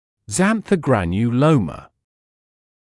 [ˌzænθəuˌgrænju’ləumə][ˌзэнсоуˌгрэнйу’лоумэ]ксантогранулема